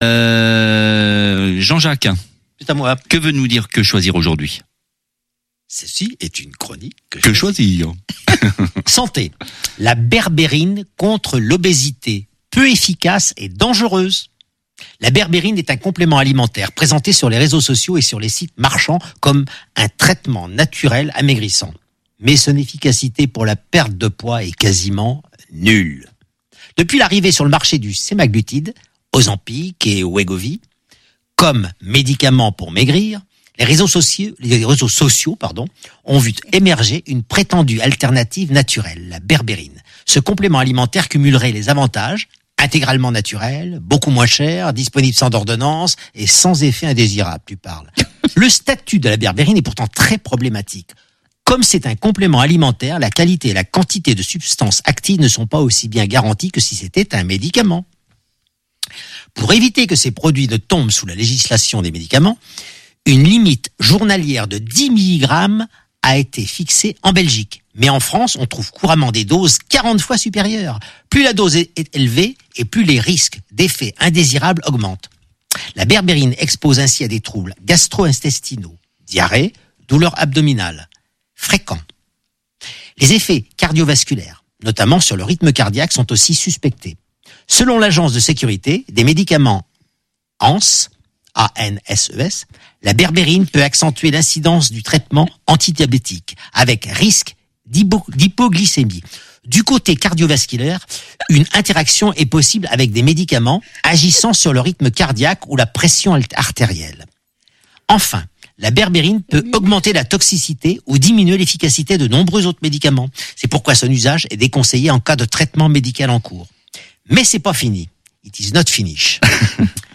dans une ambiance très décontractée , certes dans un temps limité